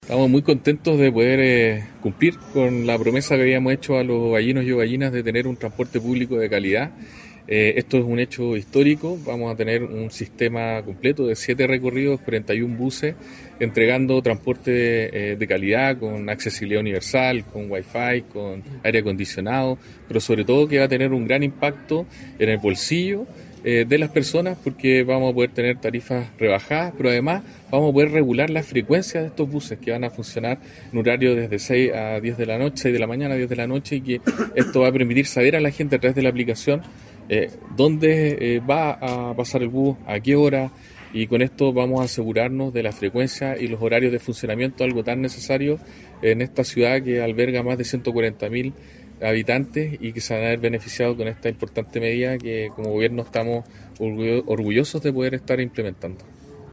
ELECTROMOVILIDAD-OVALLE-Delegado-Presidencial-Galo-Luna-Penna.mp3